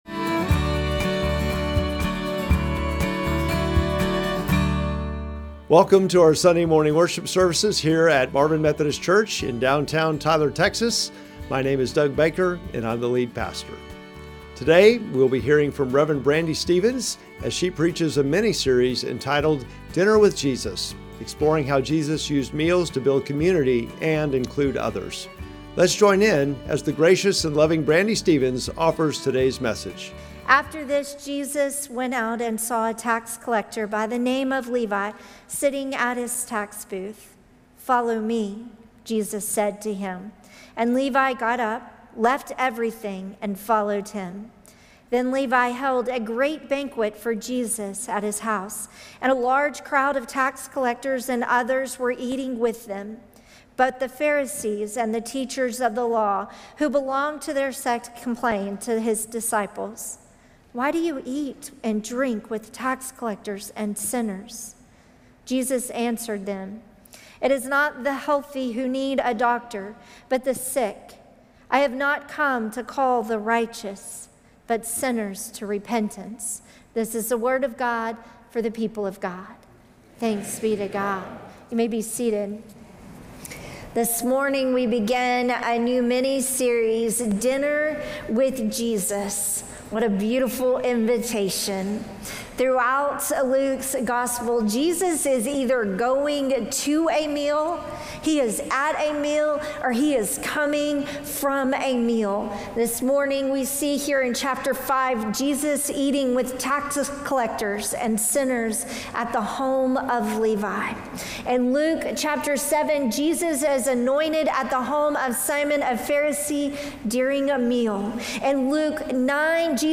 Sermon text: Luke 5:27-32